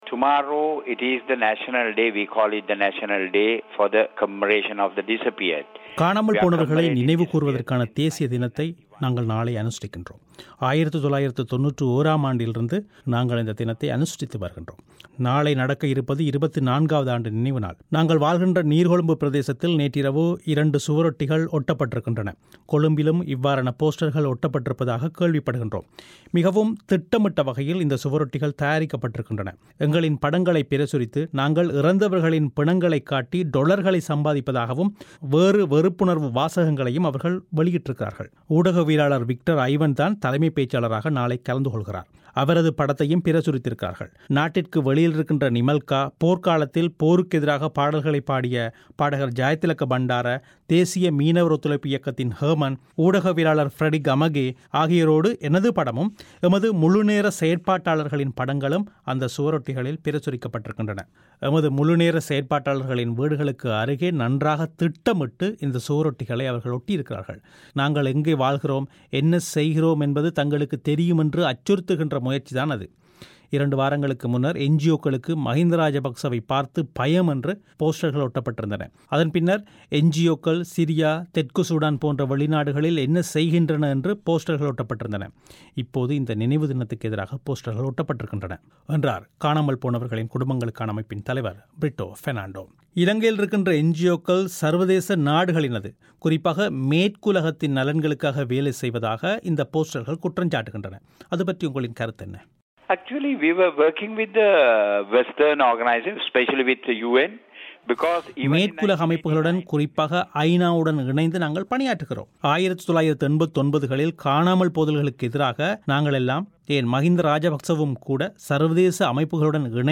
அளித்துள்ள செவ்வியிலேயே